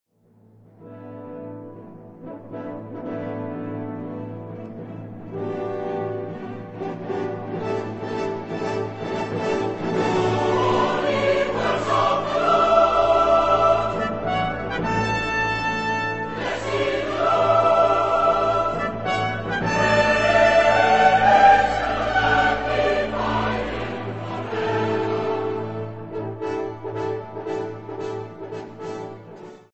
Epoque: 20th century
Type of Choir: SATB  (4 mixed voices )